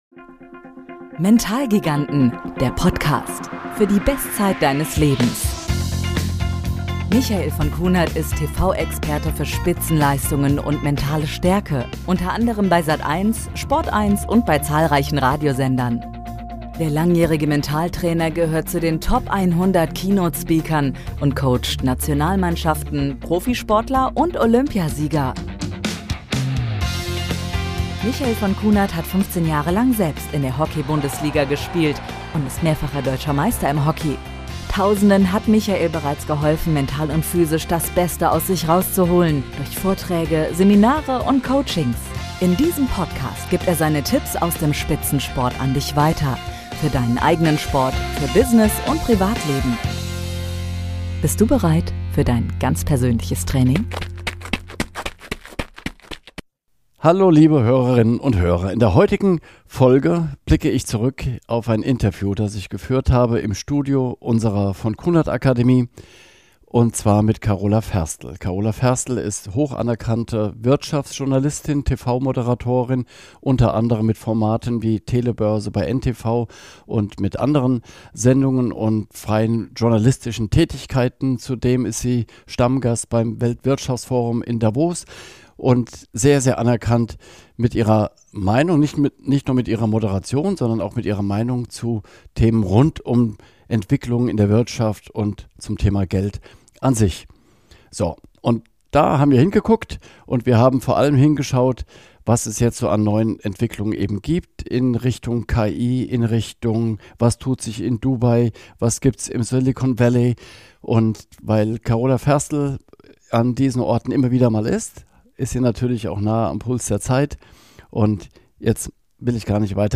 In dieser Folge blicken wir zurück auf ein faszinierendes Interview mit der renommierten Wirtschaftsjournalistin und TV-Moderatorin Carola Ferstl. Gemeinsam erkunden sie die neuesten Entwicklungen in der Wirtschaft, von künstlicher Intelligenz bis hin zu revolutionären Projekten in Dubai und Saudi-Arabien.